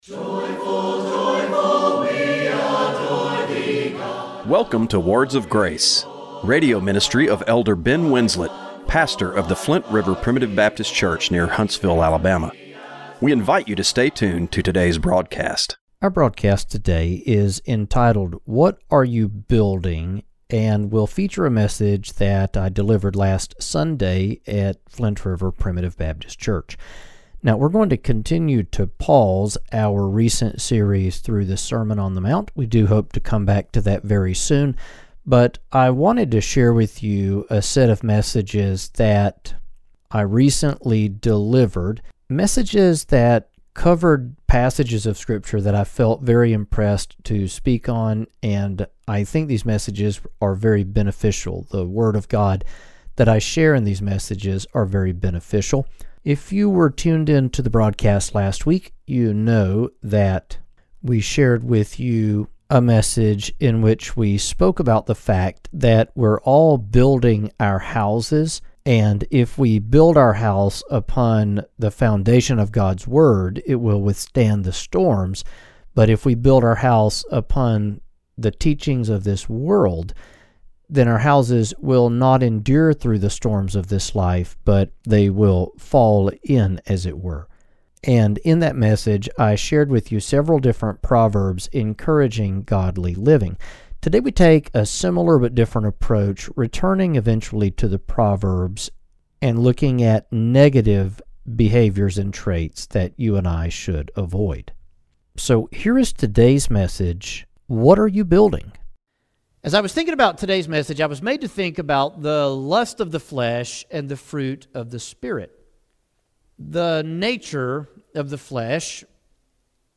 Radio broadcast for April 6, 2025.